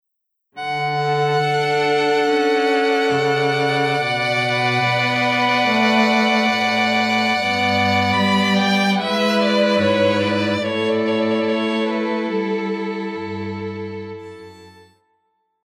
The hauntingly beautiful melody